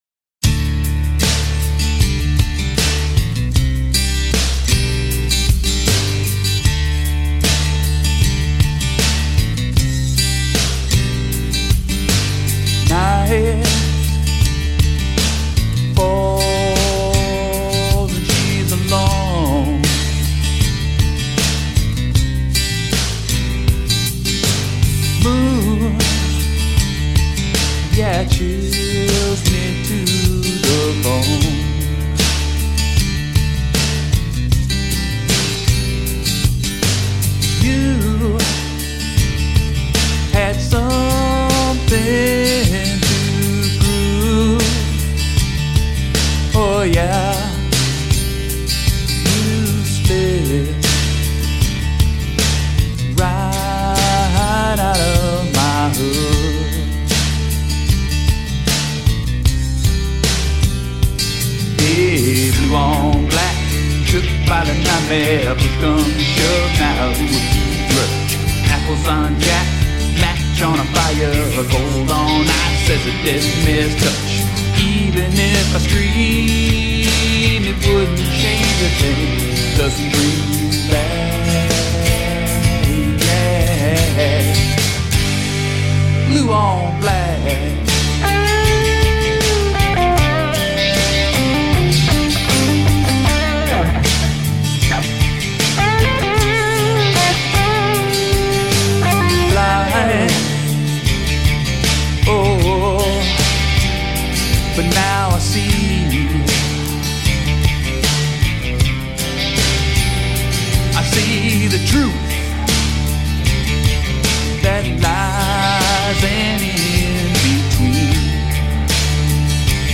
guitar solo
deep bluesy sound